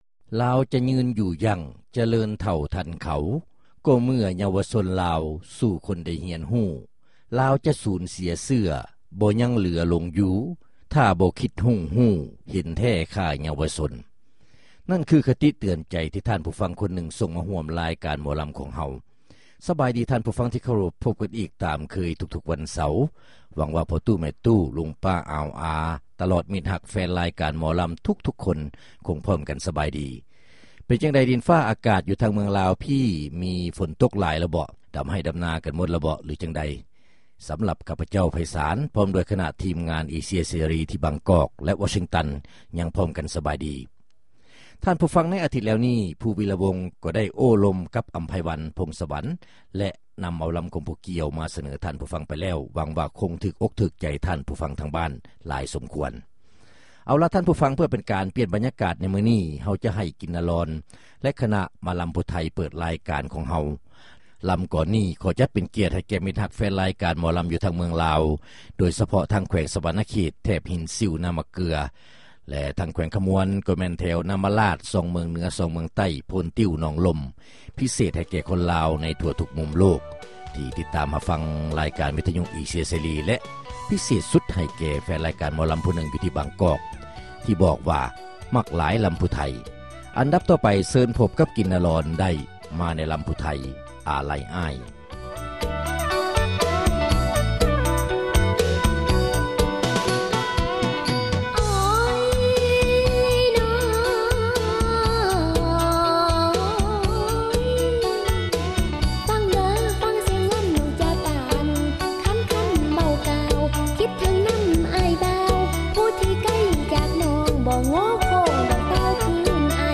ຣາຍການໜໍລຳປະຈຳສັປະດາ ວັນທີ 1 ເດືອນ ກໍຣະກະດາ ປີ 2005